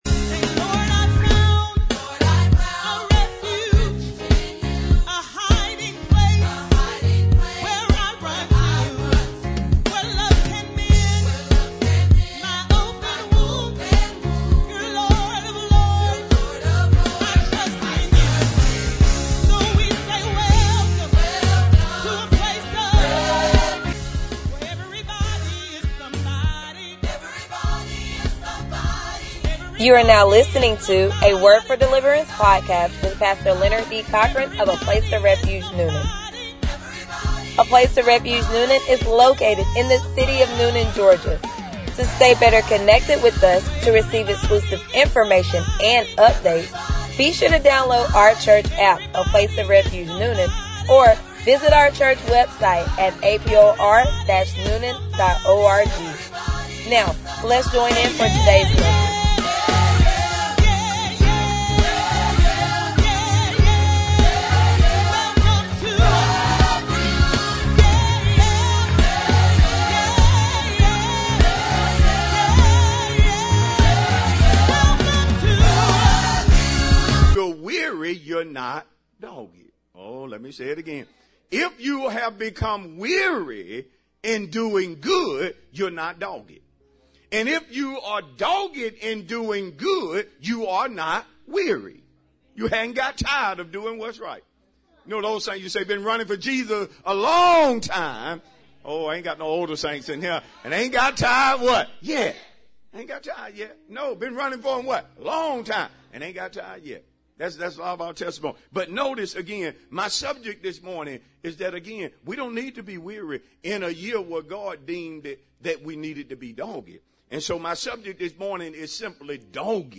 Sermons | A Place Of Refuge Newnan